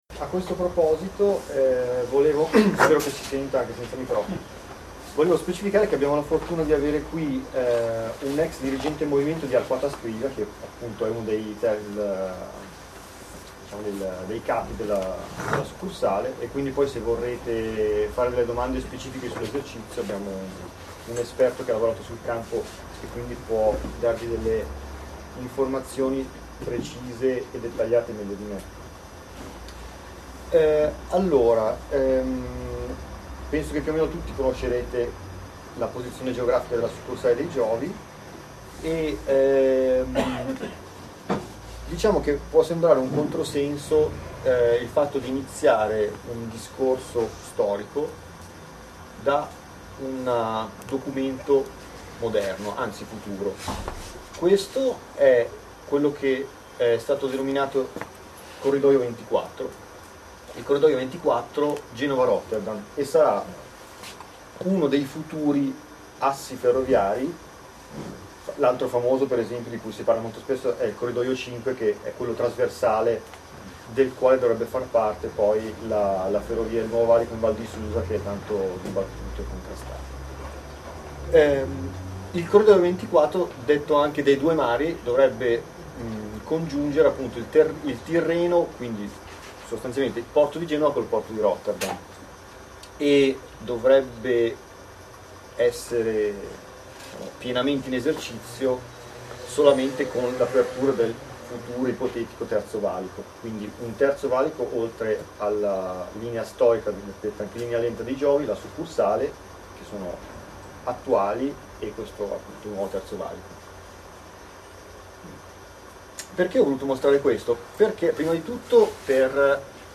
È disponibile la registrazione audio della conferenza del 18 novembre 2011: